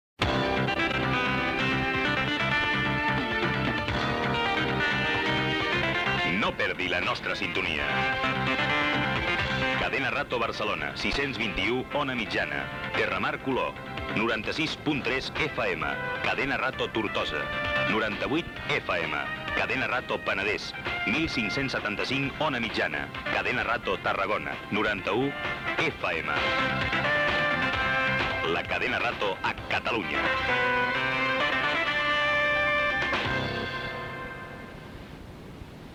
Indicatiu de l'emissora i freqüències de les emissores catalanes de la candena, inclosa Cadena Rato Panadés